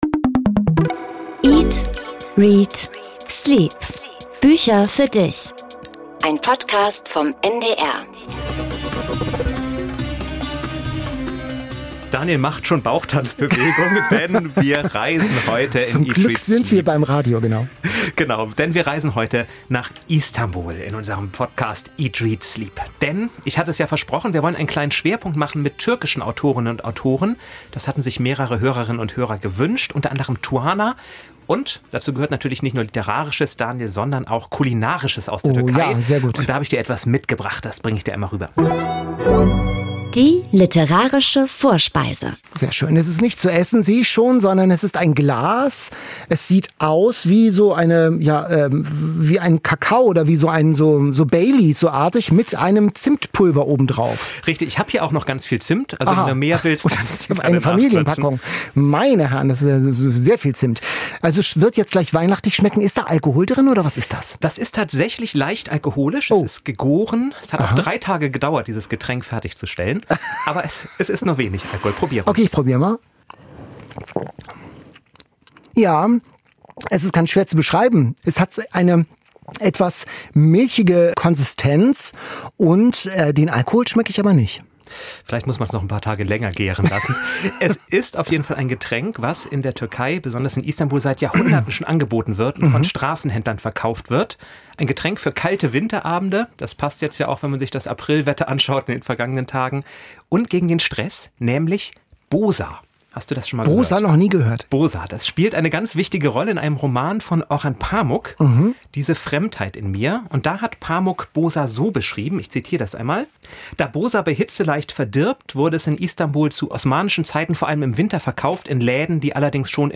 Der Gast dieser Folge, David Safier, hat darauf eine überzeugende Antwort.